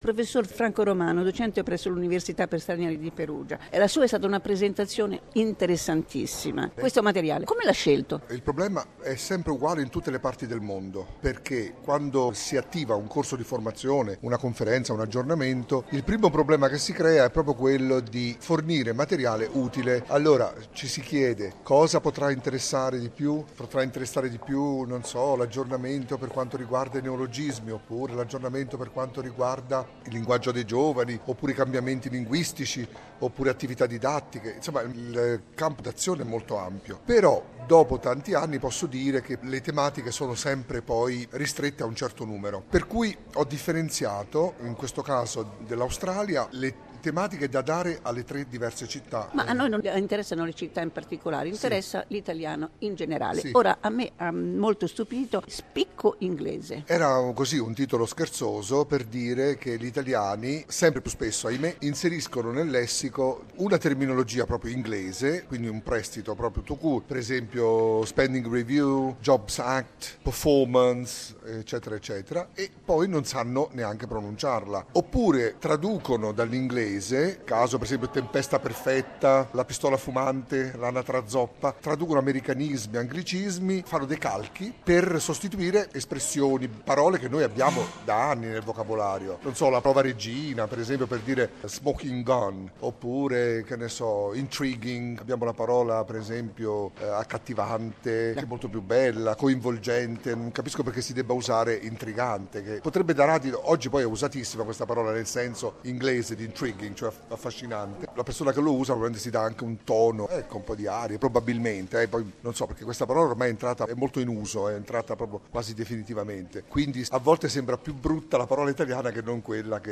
"L'italiano in evoluzione", intervista